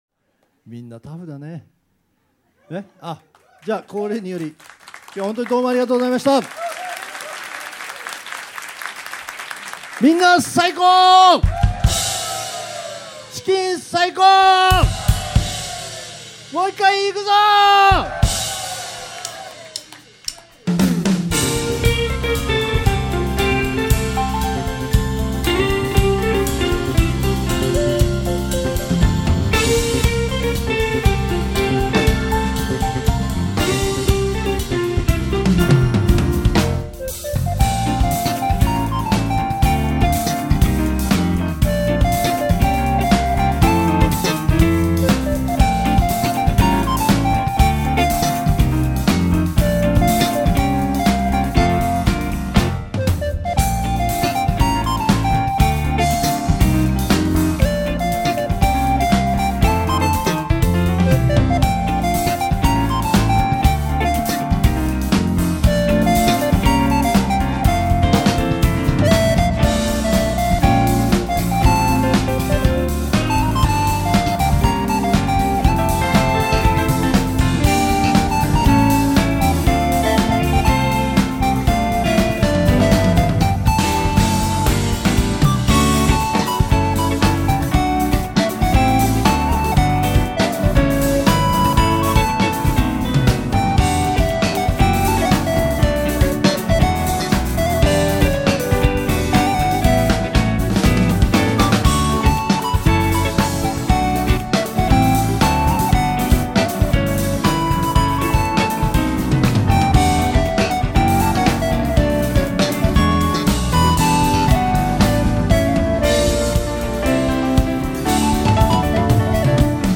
year end special live 2015,12,26